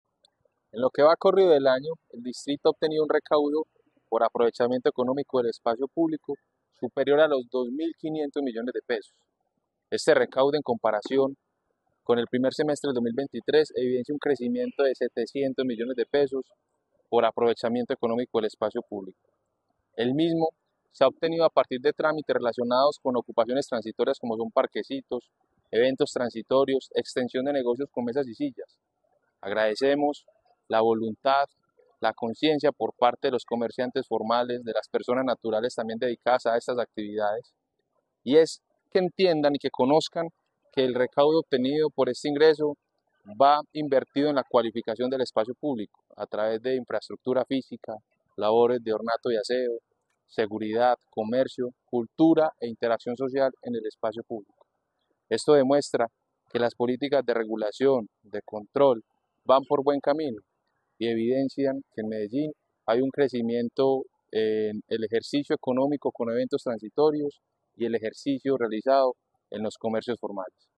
Palabras de David Ramírez, subsecretario de Espacio Público